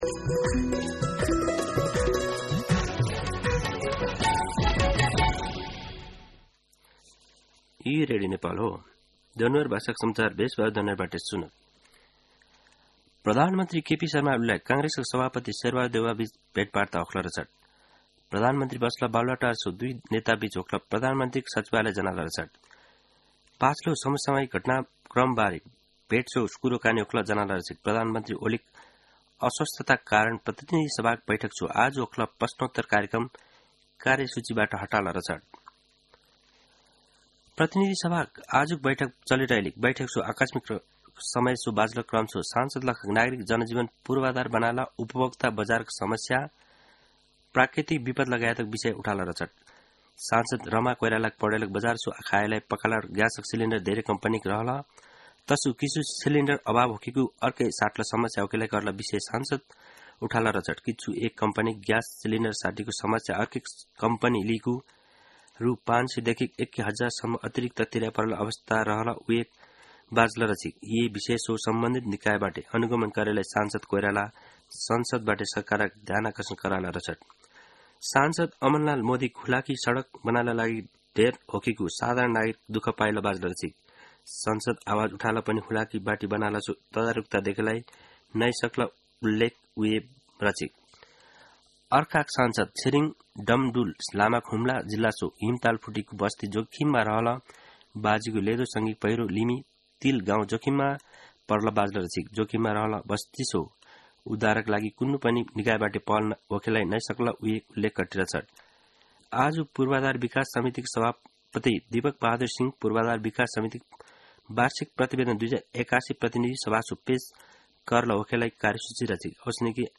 दनुवार भाषामा समाचार : ६ जेठ , २०८२
Danuwar-News-02-06.mp3